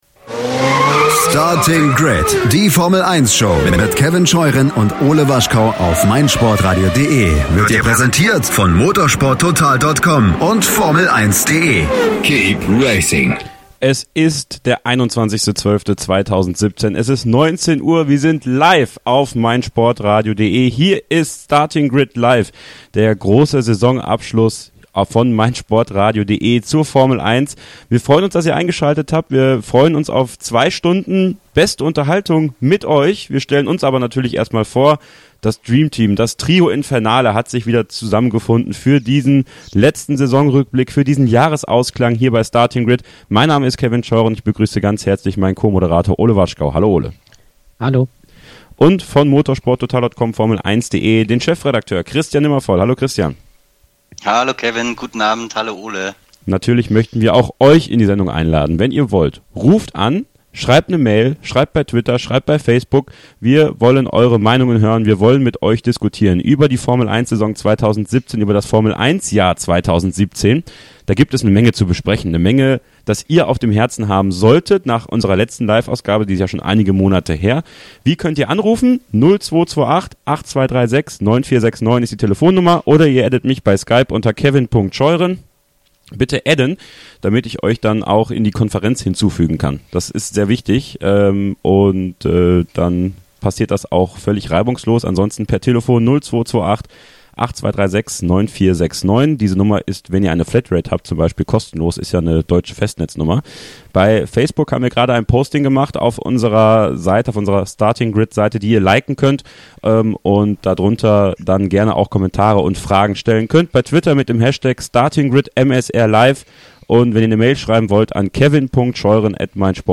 *** Diese Folge enthält Werbung *** Immer gut fahren – mit der Allianz Kfz-Versicherung.